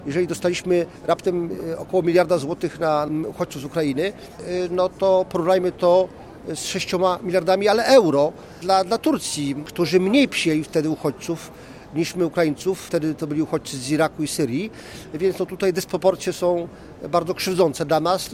Polska dostała mniejsze wsparcie niż Turcja Eurodeputowany PiS Ryszard Czarnecki w rozmowie z Radiem Łódź podkreślił, że UE nam dziękuję za pomoc, ale nie idą za tym finanse.